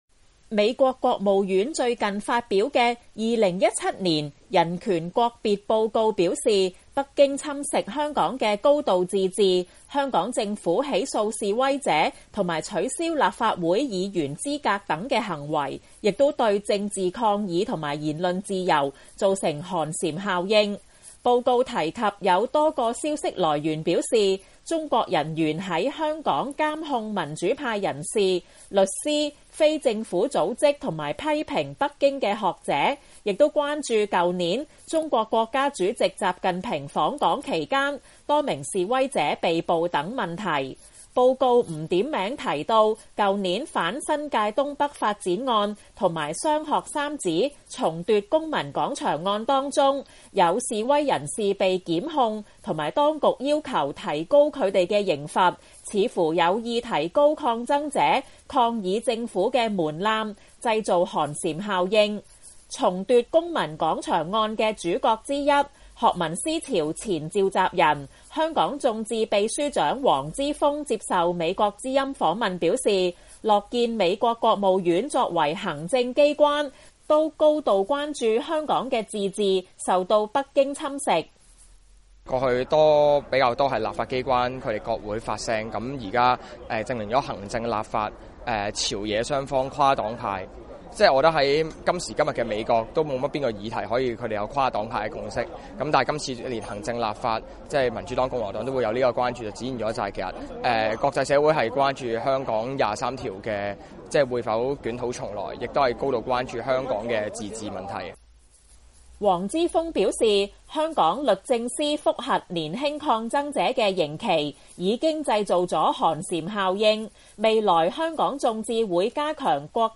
香港眾志秘書長黃之鋒等多名民主派人士接受美國之音訪問表示，樂見美國國務院關注香港的高度自治受到北京侵蝕，反映香港問題受國際社會高度關注，他們擔心民主、法治不斷倒退，影響香港的國際地位。
他們高呼口號吸引市民關注。